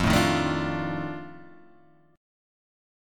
F 13th